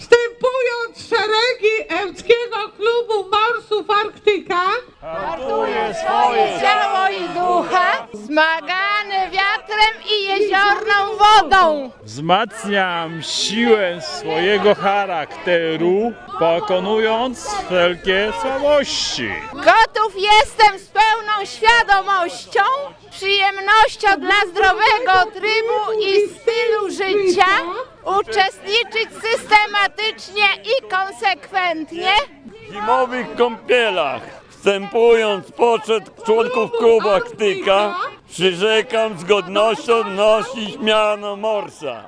Niedzielne (22.10.) Wejście do Jeziora Ełckiego poprzedziła rozgrzewka w ogrodzie Centrum Edukacji Ekologicznej i ślubowanie nowych morsów. Przyrzeczenie złożyło 20 osób.